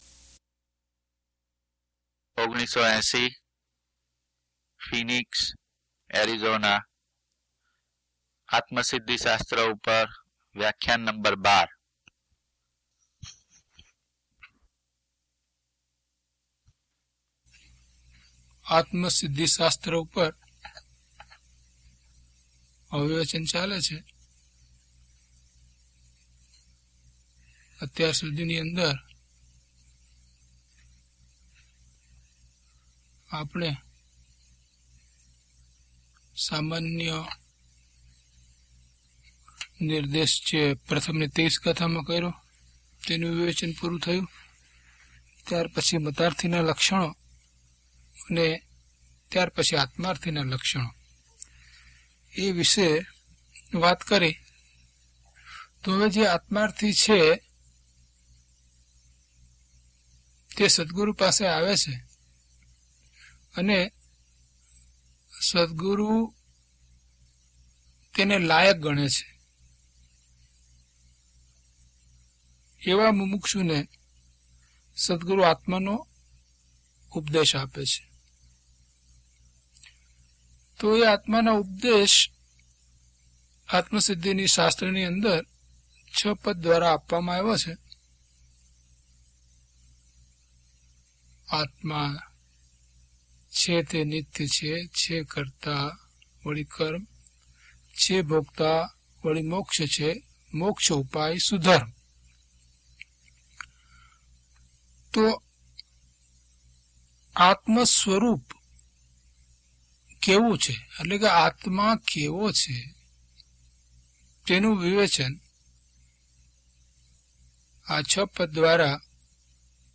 DHP021 Atmasiddhi Vivechan 12 - Pravachan.mp3